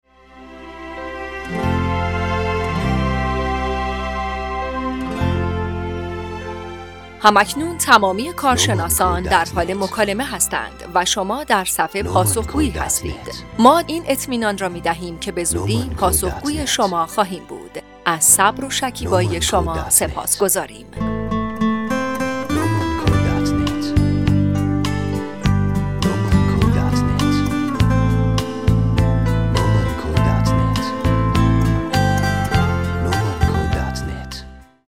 پیام انتظار برای صف پاسخگویی | نومان
دانلود-صدای-تلفن-گویا-برای-زمان-انتظار